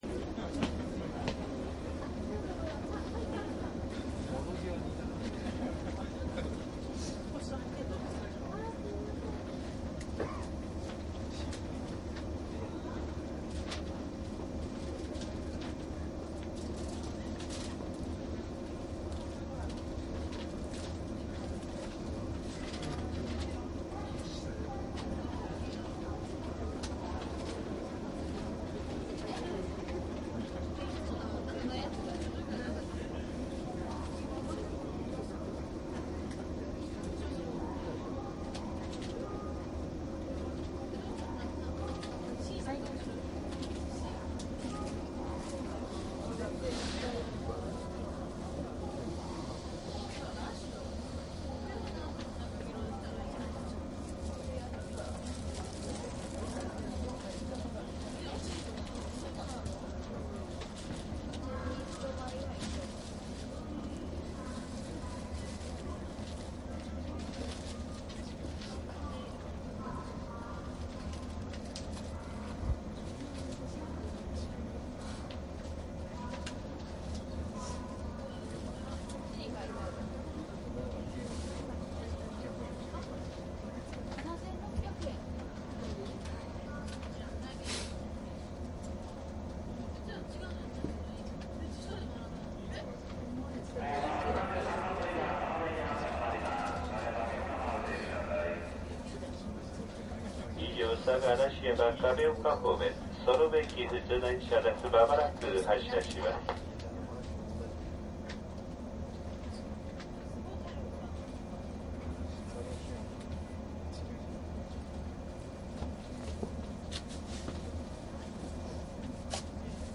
♪JR西日本 山陰線 京都口 113系 走行音  ＣＤ♪
山陰線 京都口で113系を録音したCDです。
DATかMDの通常SPモードで録音（マイクＥＣＭ959）で、これを編集ソフトでＣＤに焼いたものです。